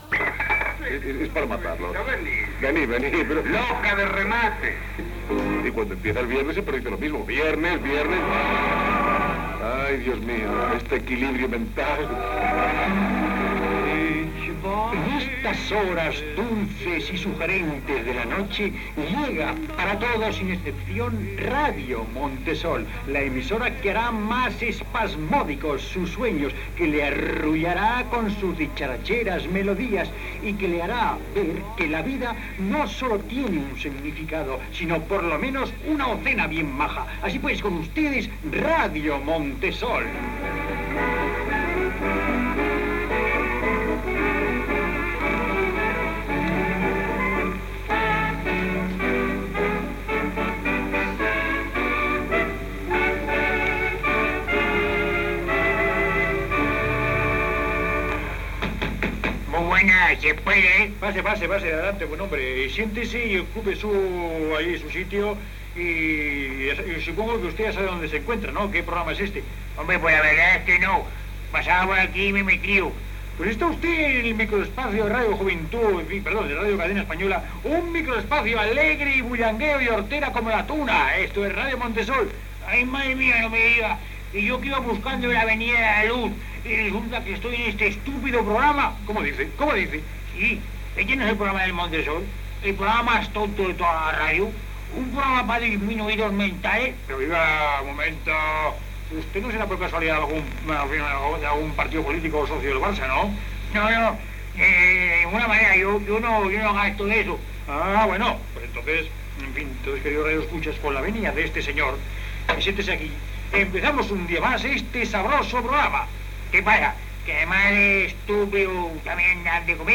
"Radio Montesol", careta de l'espai, una persona puja a l'emissora sense saber on va
Entreteniment
FM